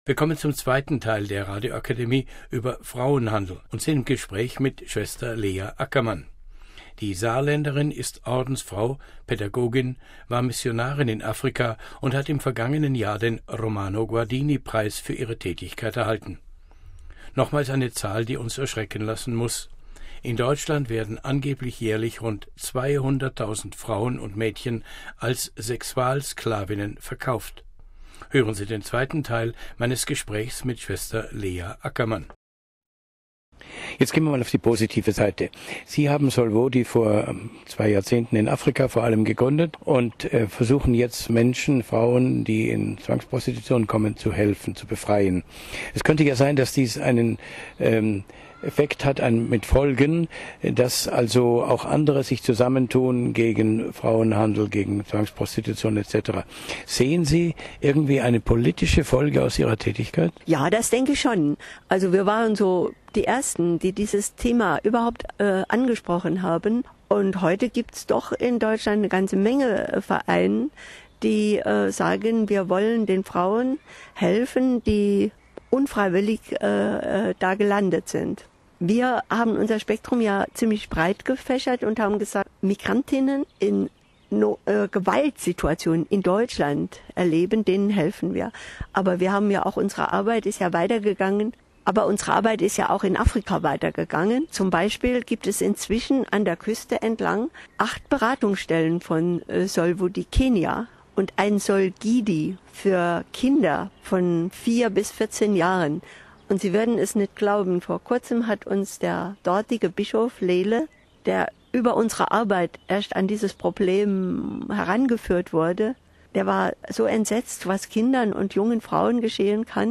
Home Archivio 2009-06-30 17:21:29 D: Frauenhandel - Sklaverei heute (2) Hören Sie Teil zwei unseres Gesprächs mit Sr. Lea Ackermann.